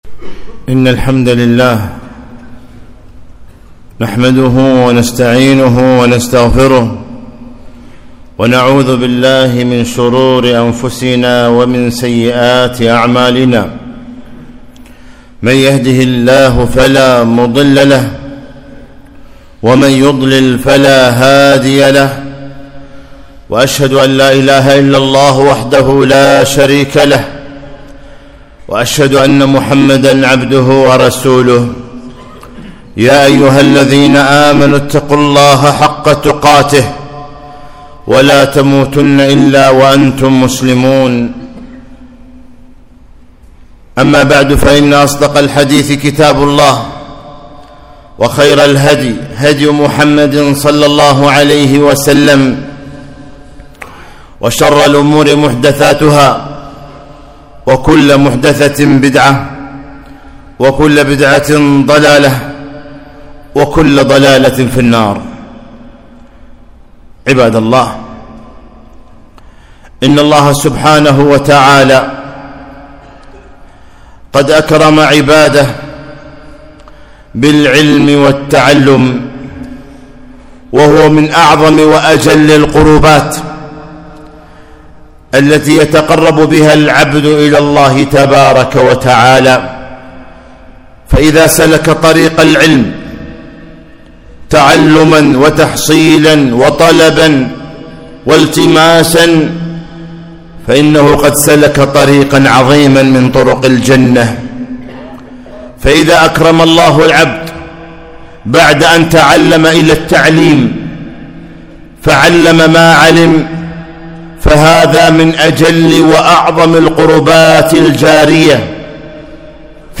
خطبة - عام دراسي جديد